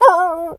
dog_hurt_whimper_howl_10.wav